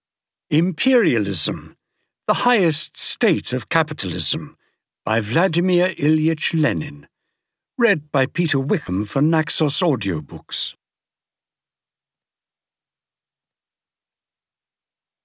Imperialism (EN) audiokniha
Ukázka z knihy